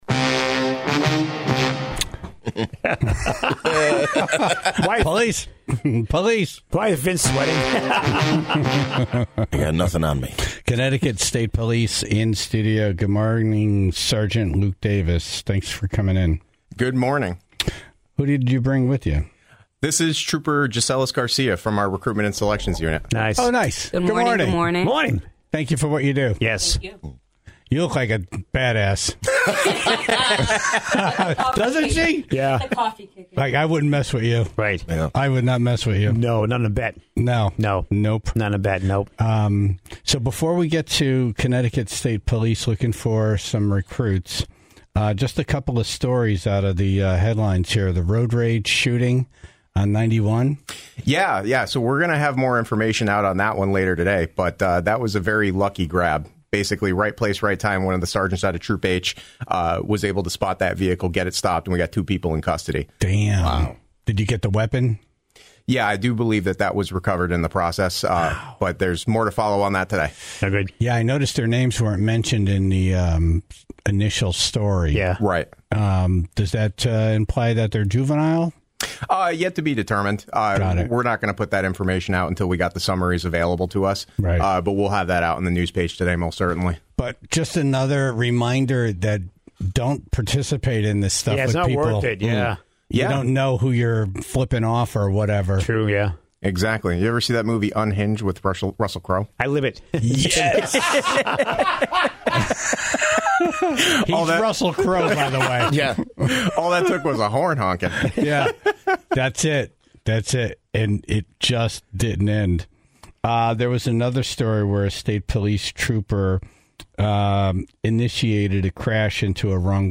Pod Pick: CT State Police in Studio
Recruitment is active, with salaries starting around $60,000. The Tribe called in their questions about background checks and smoking marijuana.